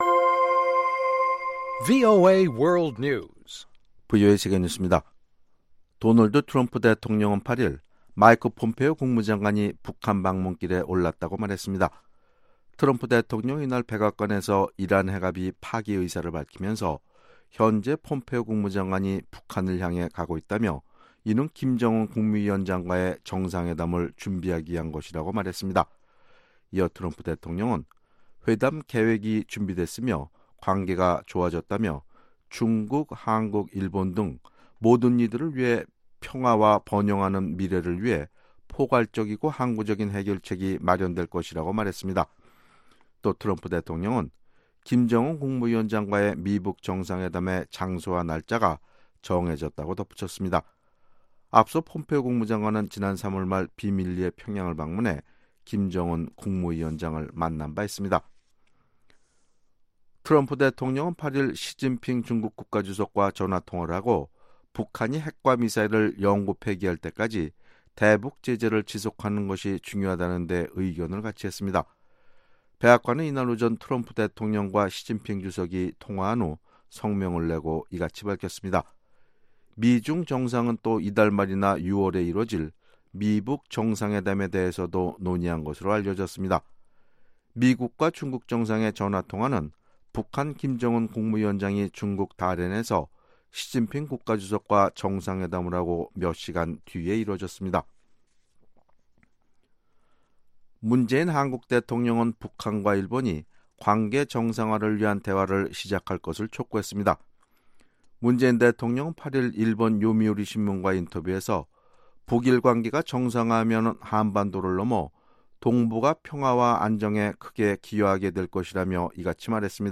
VOA 한국어 방송의 아침 뉴스 프로그램 입니다.